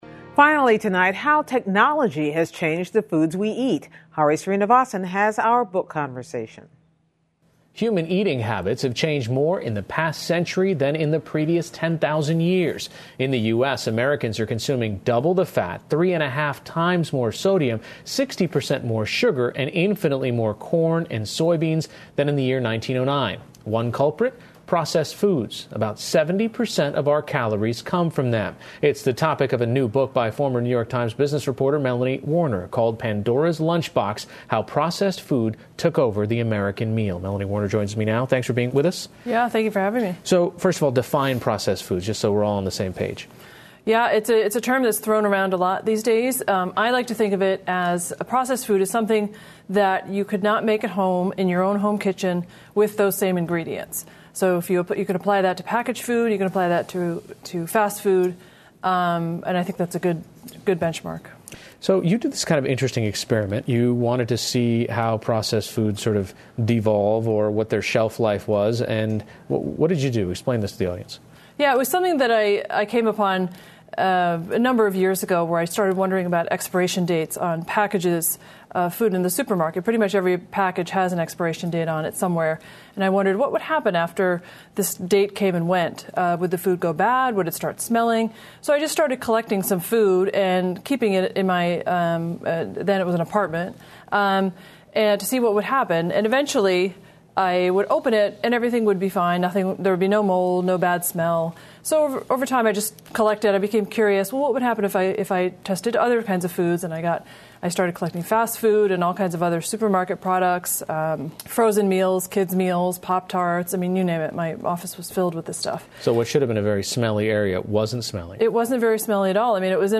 英语访谈节目:对美国饮食而言加工食品是潘多拉魔盒吗?